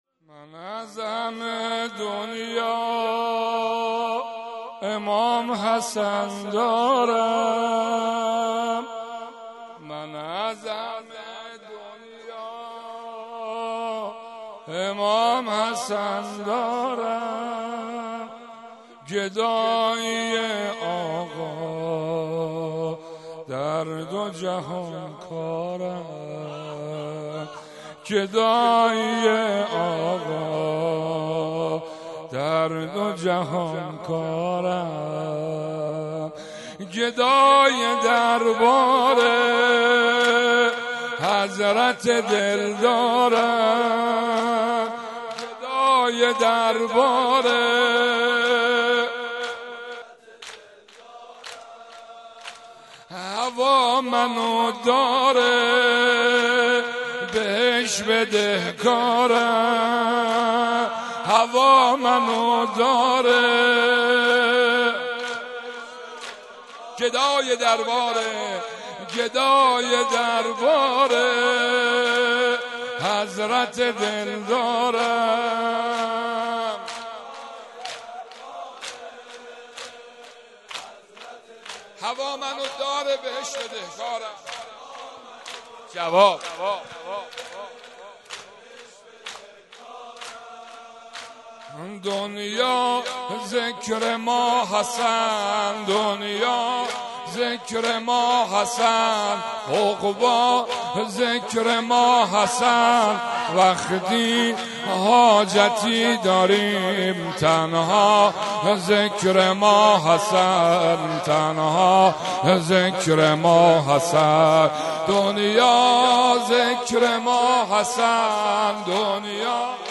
05.sineh zani.mp3